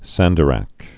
(săndə-răk)